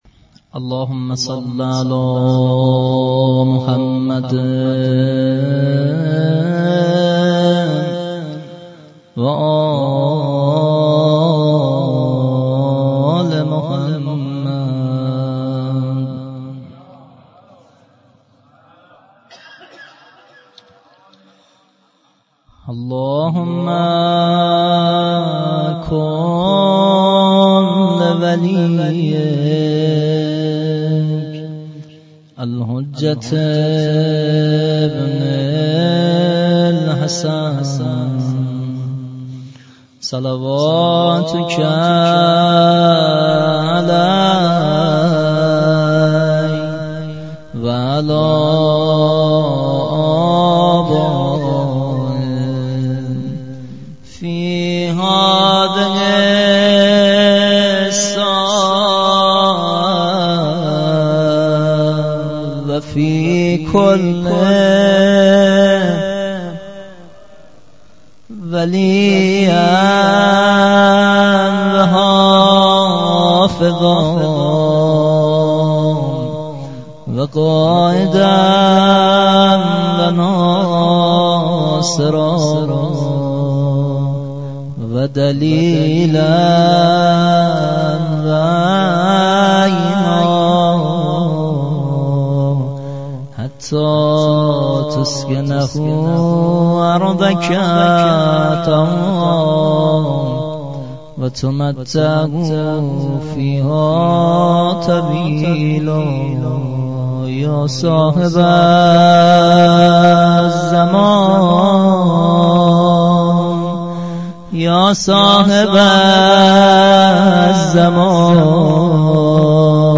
مراسم هفتگی
مداحی